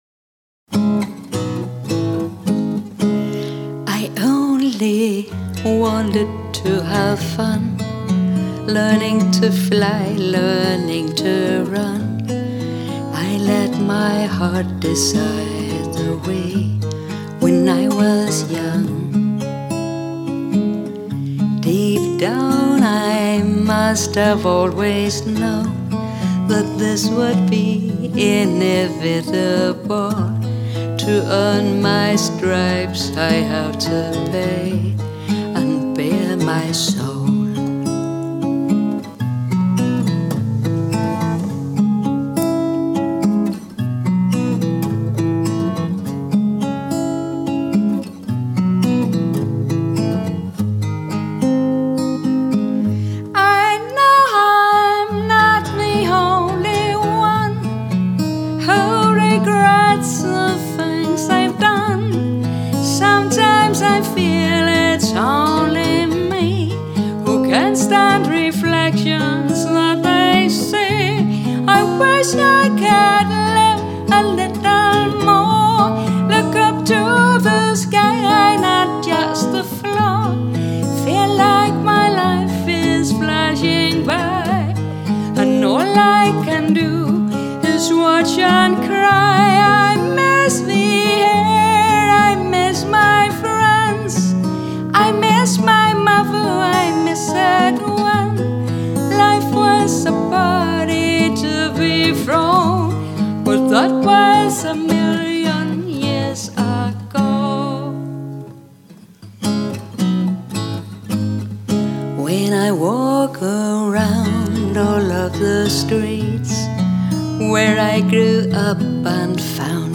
Lyt til min video og lydklip med mig og min guitar.